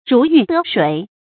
注音：ㄖㄨˊ ㄧㄩˊ ㄉㄜ ㄕㄨㄟˇ
如魚得水的讀法